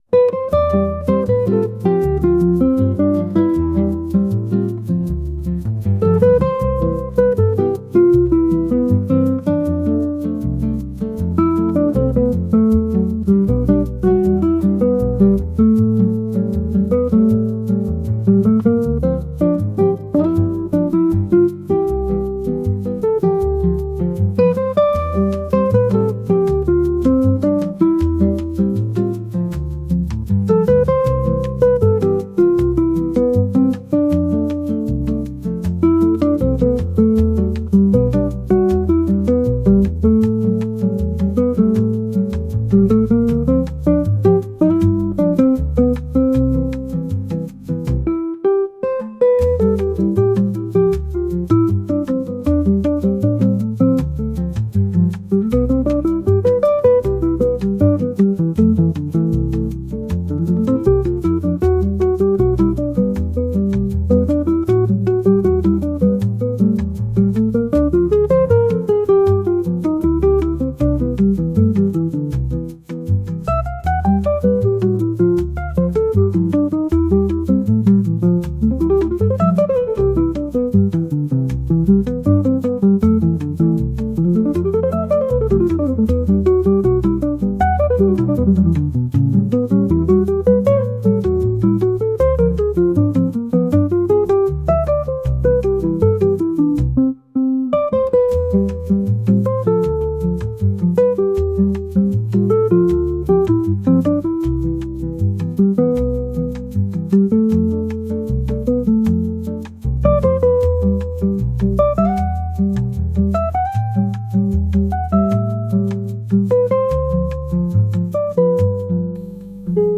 ジャンルボサノバ
楽曲イメージゆったり, カフェ, , 爽やか